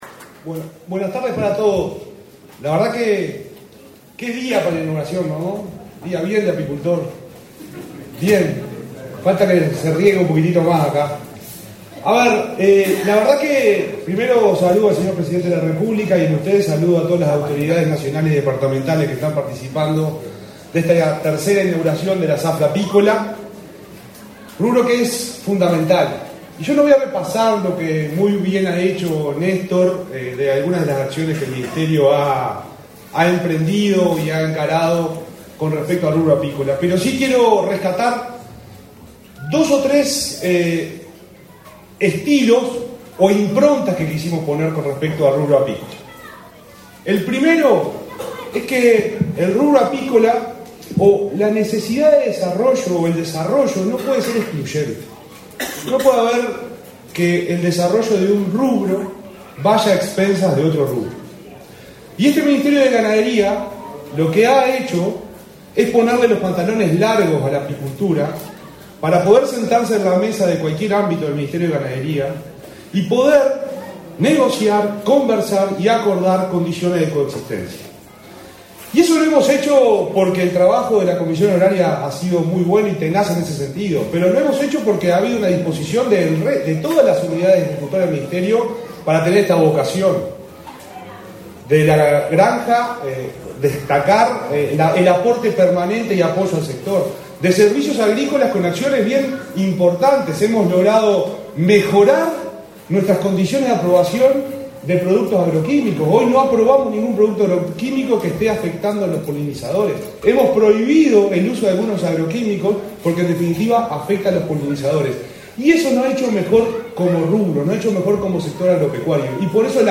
Palabras del subsecretario del MGAP, Juan Ignacio Buffa
Palabras del subsecretario del MGAP, Juan Ignacio Buffa 06/11/2024 Compartir Facebook X Copiar enlace WhatsApp LinkedIn El presidente de la República, Luis Lacalle Pou, participó, este 6 de noviembre, en el lanzamiento de la zafra de la miel 2024, en la localidad de Sarandí Grande, en el departamento de Florida. En el evento disertó el subsecretario del Ministerio de Ganadería, Agricultura y Pesca (MGAP), Juan Ignacio Buffa.